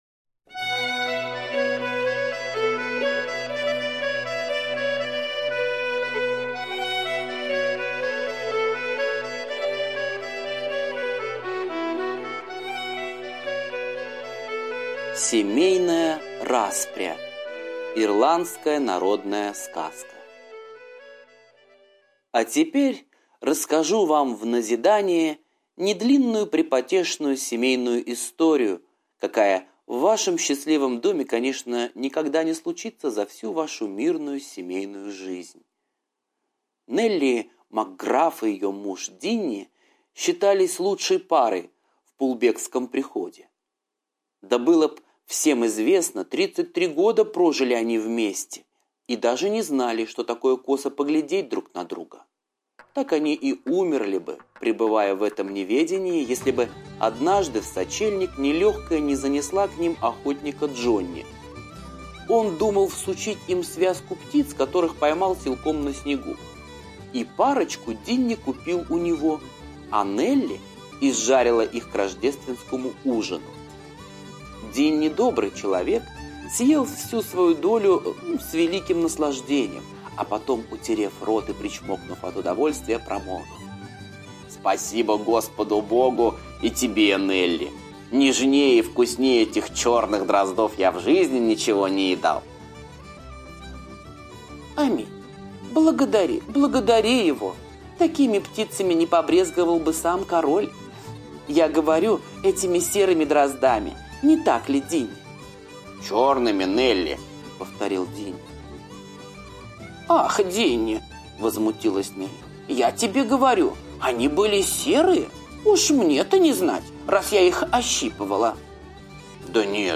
Семейная распря - ирландская аудиосказка - слушать онлайн